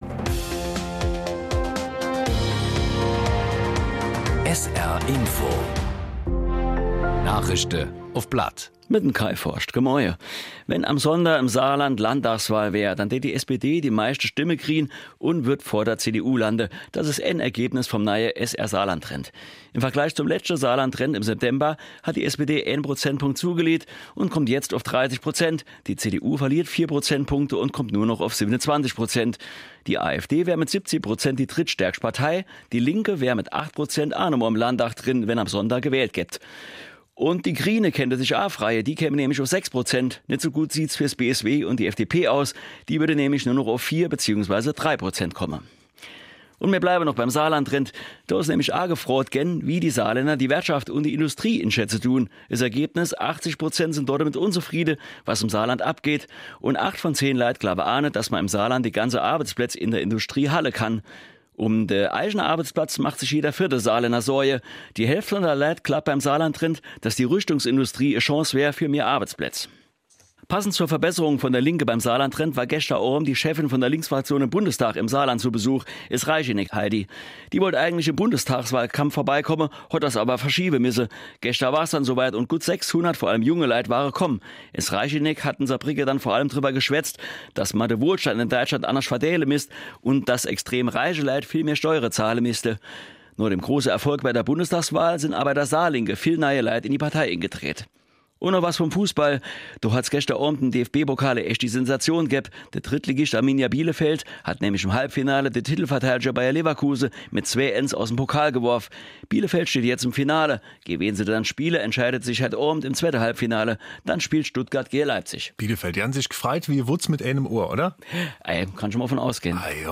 Von Montag bis Freitag gibt es bei SR 3 Saarlandwelle täglich um 08:30 Uhr Schlagzeilen in Mundart. Die "Nachrischde uff platt" werden mal in moselfränkischer, mal in rheinfränkischer Mundart präsentiert. Von Rappweiler bis Dudweiler, von Dillingen bis Püttlingen setzt sich das Team aus Sprechern ganz verschiedener Mundartfärbungen zusammen